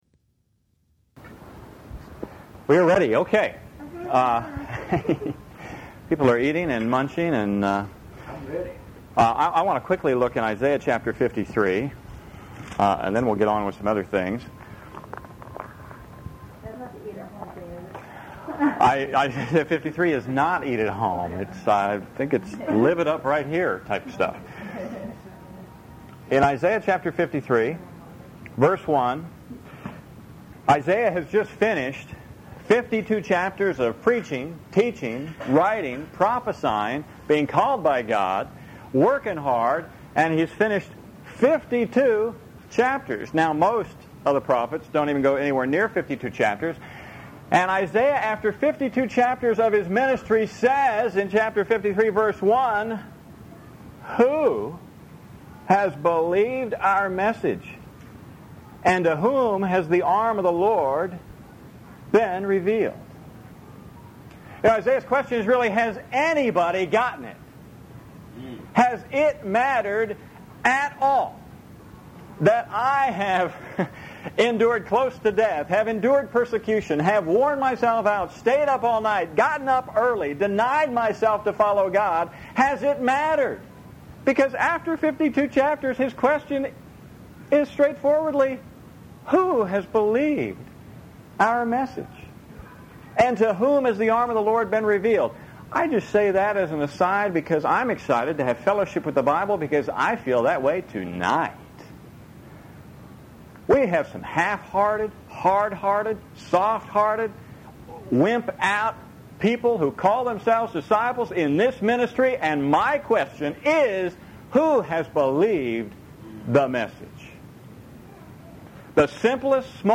This message features the reading and preaching of the following Scriptures: Isaiah 53:1 Isaiah 55:8-11 Matthew 12:30 Luke 15:11-20 Philippians 1:3-11 Instructions: To download on a Mac, control-click the message link below and select a download option.